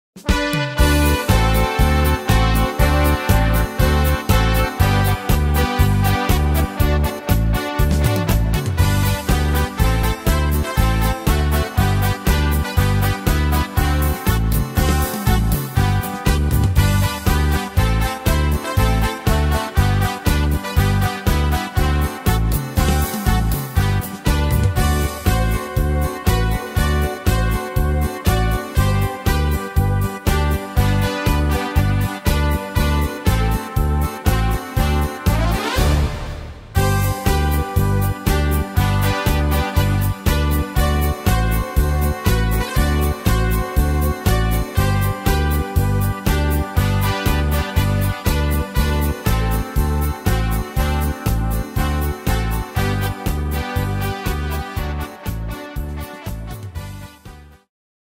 Tempo: 120 / Tonart: G-Dur